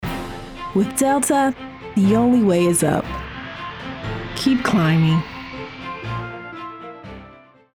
Female
Yng Adult (18-29), Adult (30-50)
My voice is warm, confident, friendly, and versatile, adapting to the needs of each project.
Radio Commercials
Words that describe my voice are Friendly, Relatable, Casual.